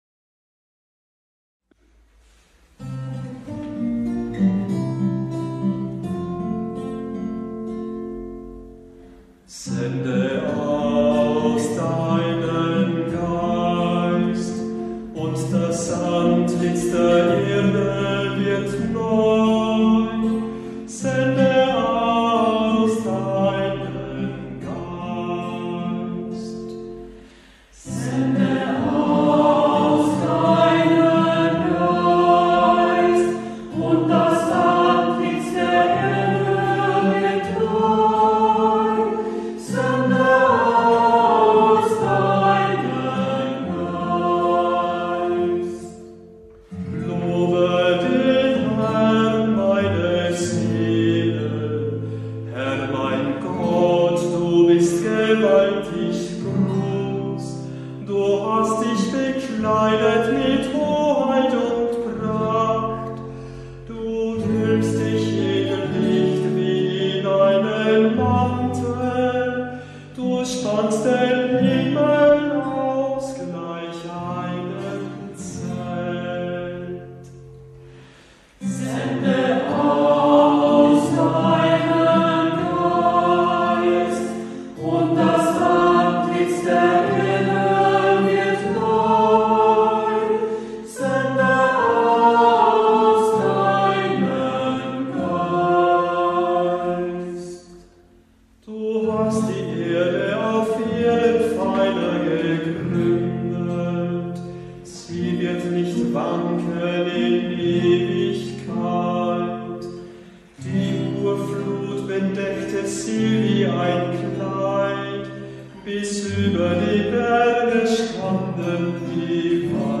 Psalm 104, Gurker Psalter, Kv „Sende aus deinen Geist“ (Kanon),